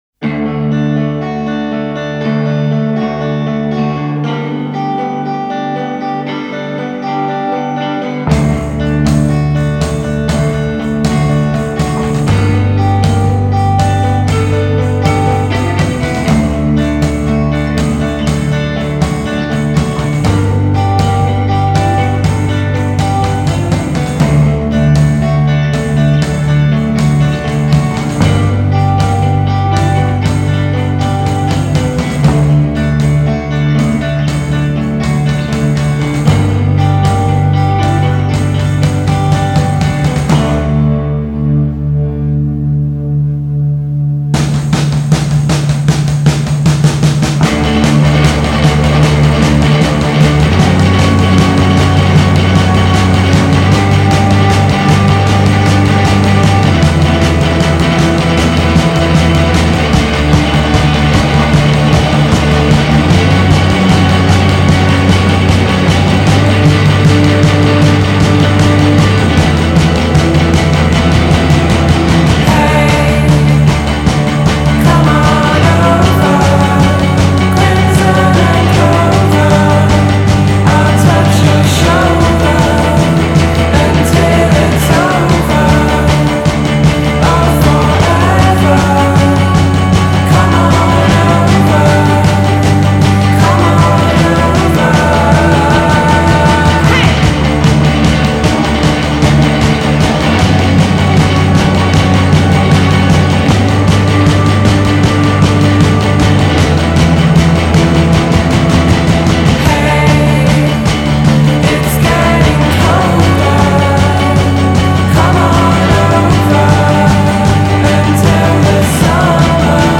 il crescendo poderoso